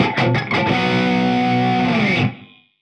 Index of /musicradar/80s-heat-samples/85bpm
AM_HeroGuitar_85-B01.wav